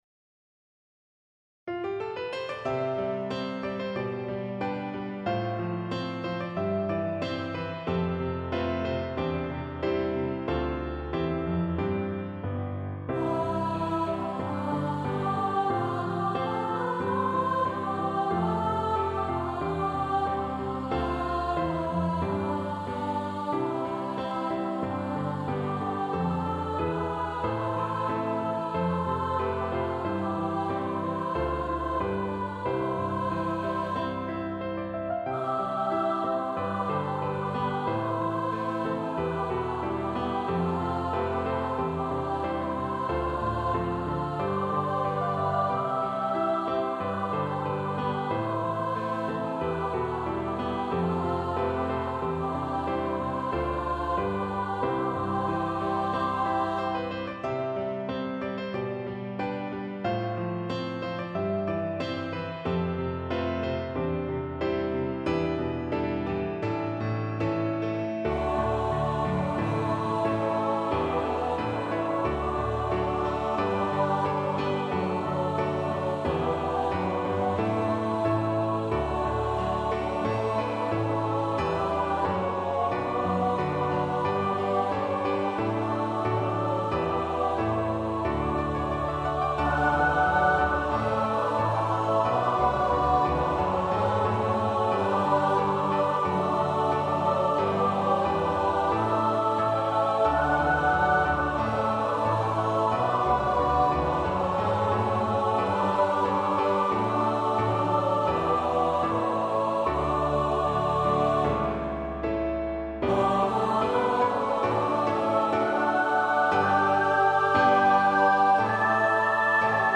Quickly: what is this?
SATB, SSAATB, SSATB